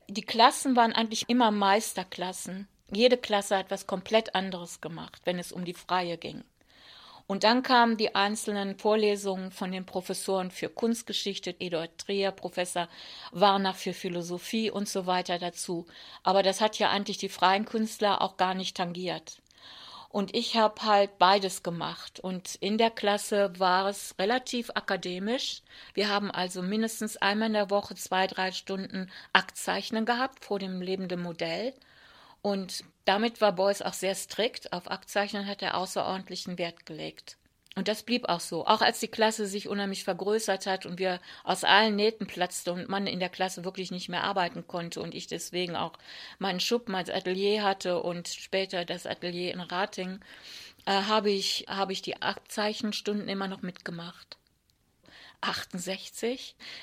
Interview Audioarchiv Kunst:
Ulrike Rosenbach berichtet im Interview übe die Kunstakademie in den 1960er Jahren.